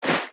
Sfx_creature_babypenguin_swim_fast_02.ogg